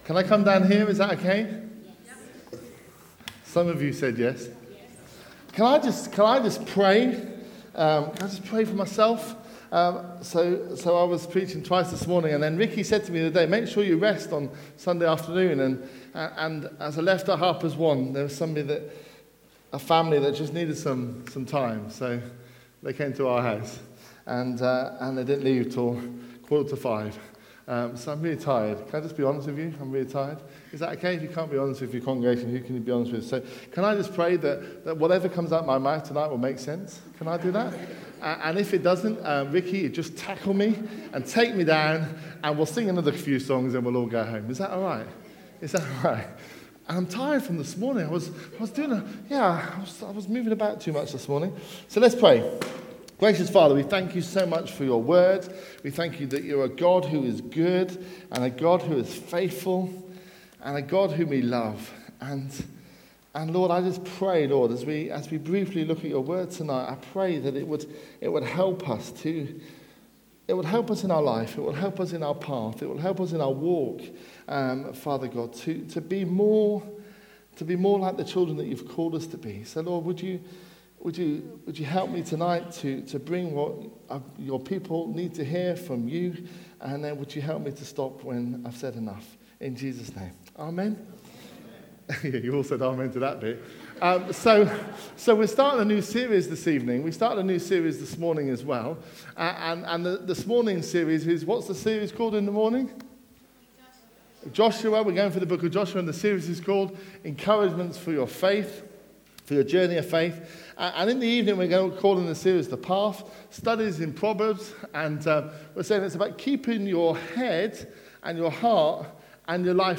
A message from the series "The Path."